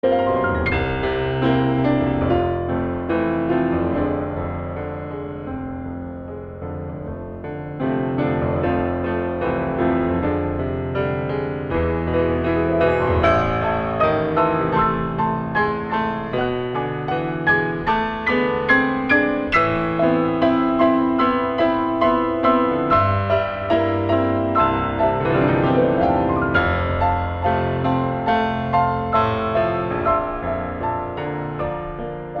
All recorded on a beautiful Steinway grand piano
in a Manhattan recording studio.